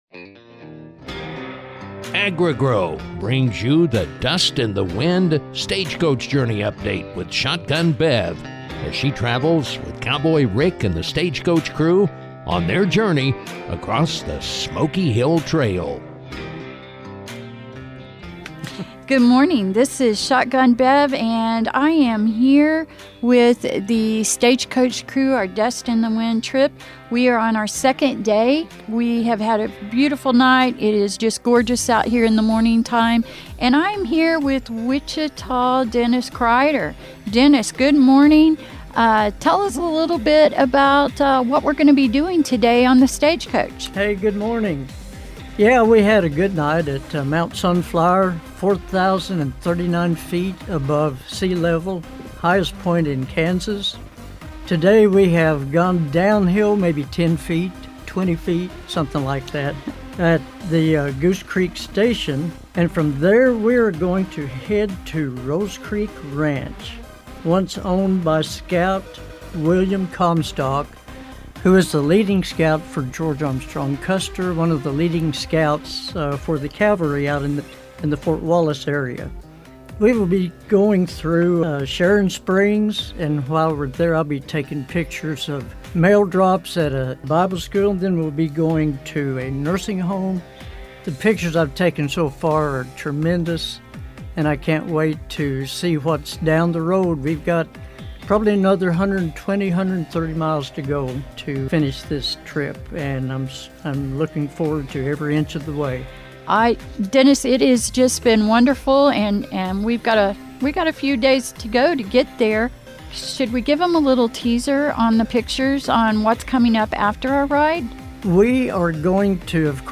The historic Silver Dollar City Journey Stagecoach takes the crew on an adventure into the Wild West this year on the “Dust In The Wind” journey. The crew is following the original Old Smoky Hill Trail from the border of Colorado into Kansas. The journey includes stops and interviews